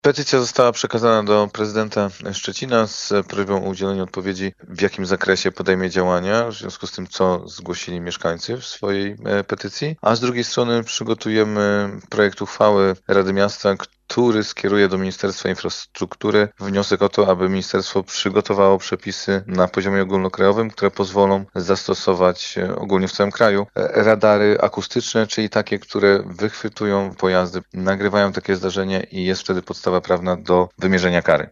Przemysław Słowik, Przewodniczący Komisji ds. Gospodarki Komunalnej, Mobilności, Klimatu i Zwierząt RM w Szczecinie:
SZCZ-Slowik-petycja.mp3